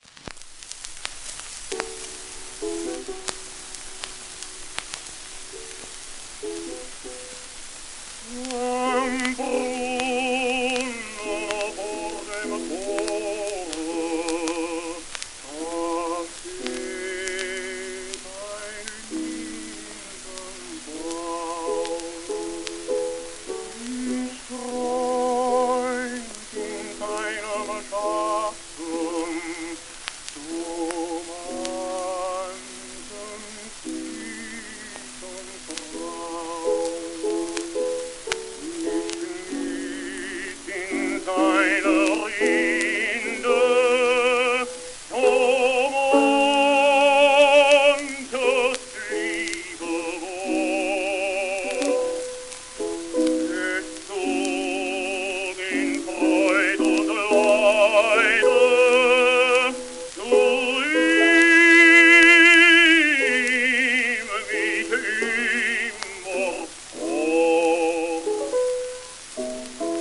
w/ピアノ
1921年頃録音
旧 旧吹込みの略、電気録音以前の機械式録音盤（ラッパ吹込み）